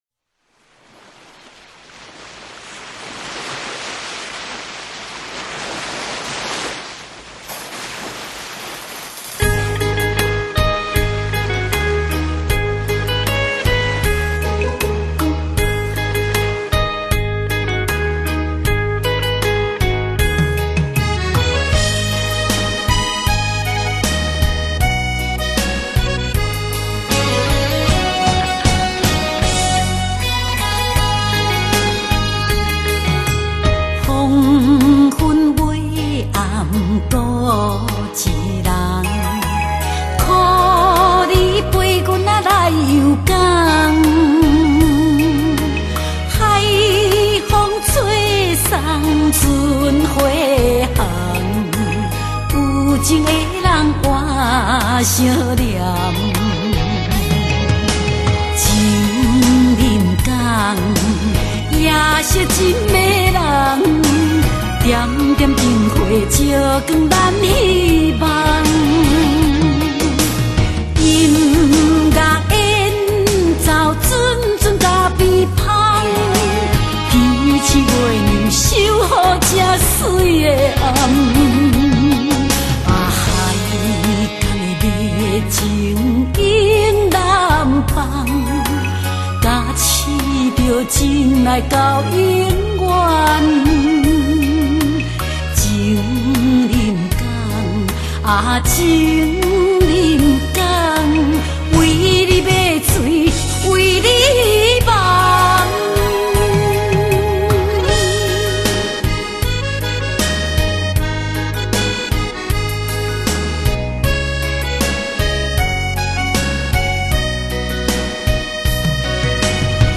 華語台語